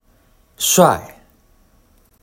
読み方は「shuài（シュアイ）」で、聲調は四聲なので語尾を下げるように発音します。
▼「帥（カッコいい）」発音サンプル
※発音サンプルは、中国語ネイティブの台湾人の方にお願いしました。